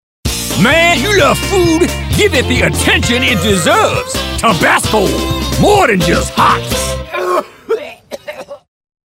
USA. All-American authority and highly versatile characters. 'Toon Pro.